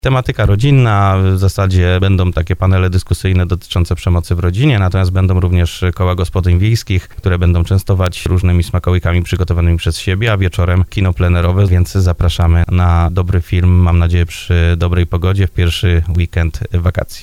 Jak mówi wójt gminy Lisia Góra Arkadiusz Mikuła, festyn to przyjemne i pożyteczne rozpoczęcie wakacji.